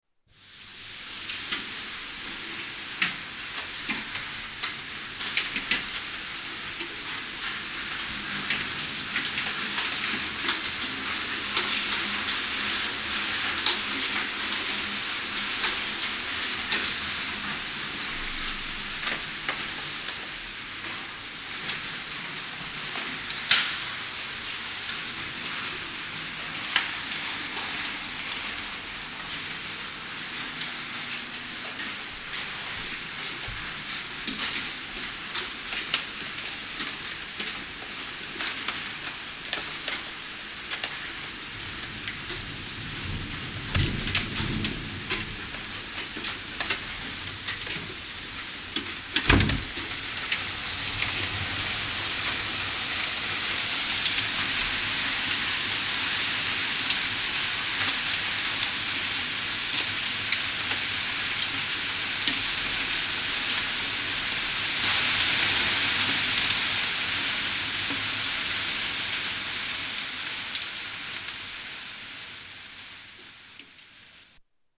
Hail and heavy rain
Tags: Travel Sounds of Austria Austria Holidays Vienna